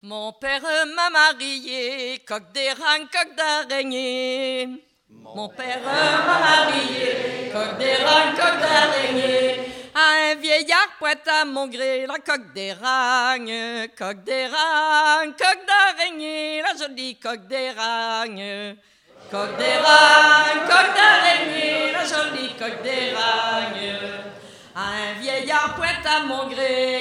danse : ronde : rond de l'Île d'Yeu
Festival du chant traditionnel
Pièce musicale inédite